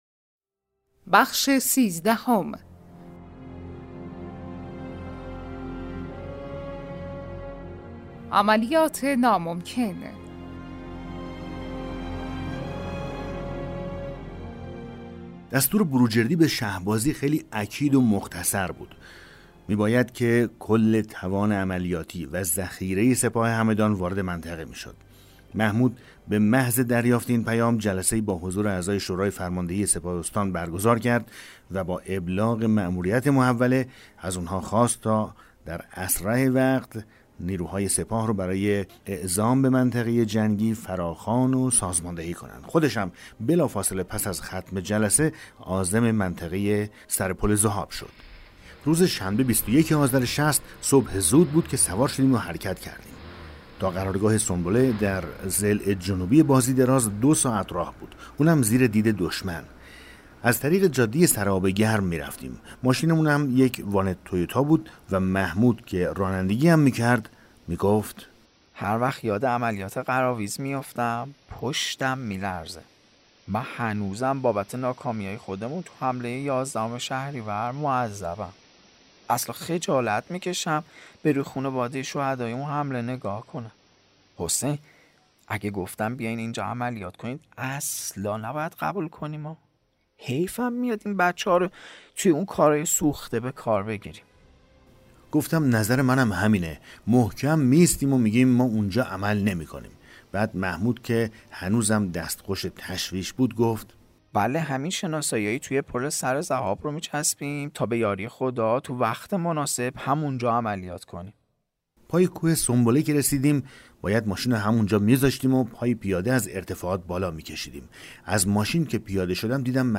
کتاب صوتی پیغام ماهی ها، سرگذشت جنگ‌های نامتقارن حاج حسین همدانی /قسمت 13